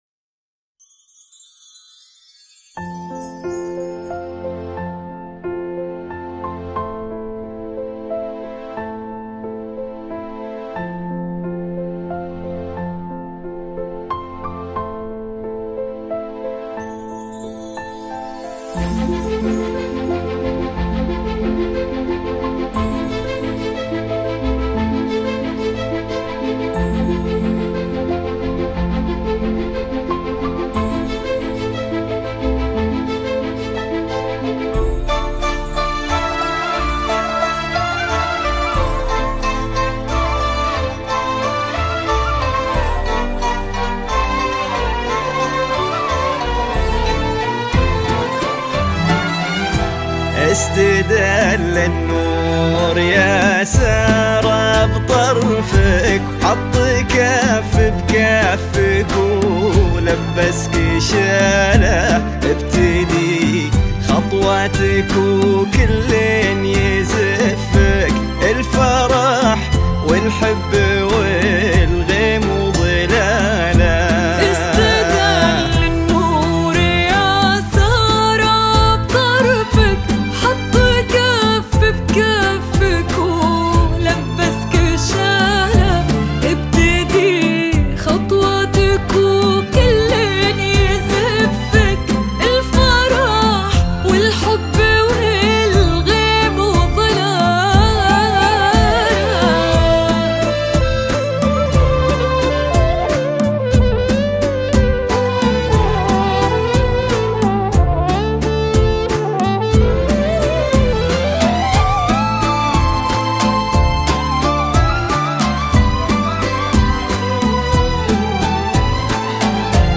تصنيف الاغنية : زفة عروس